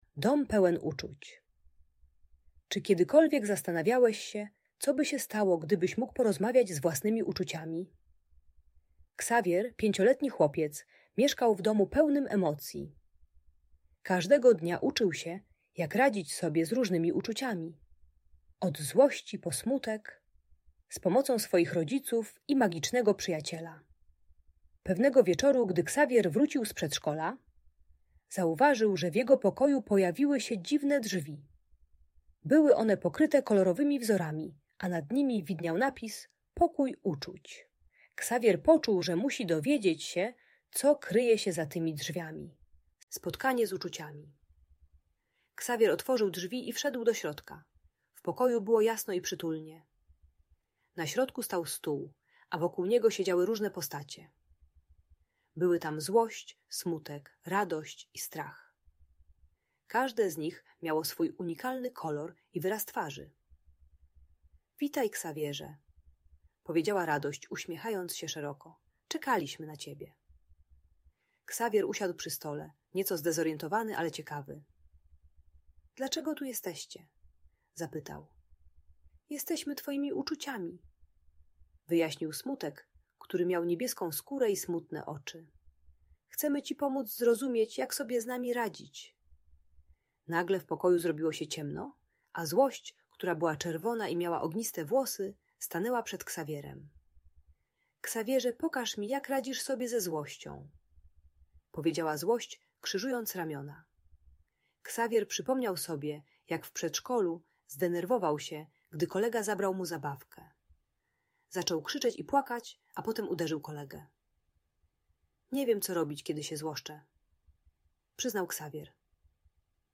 Dom Pełen Uczuć - Bunt i wybuchy złości | Audiobajka
Ksawier uczy się rozpoznawać emocje złości, smutku i strachu oraz techniki głębokiego oddychania, która pomaga się uspokoić zamiast krzyczeć czy bić. Audiobajka o radzeniu sobie z emocjami dla przedszkolaka.